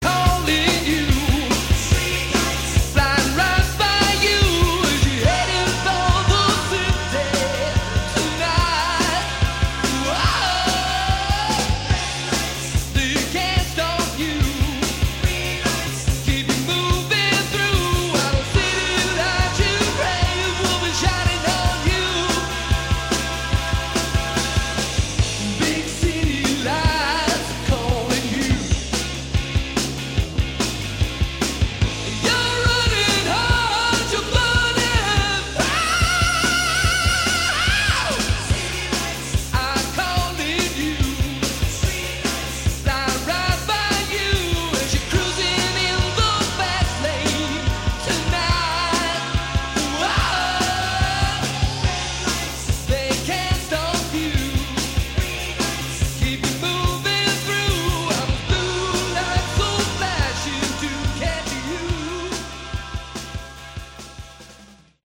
Category: Hard Rock
All Guitars
Drums, Percussion
Vocals, Screams
Bass, Keyboards, Vocals